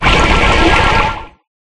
Water4.ogg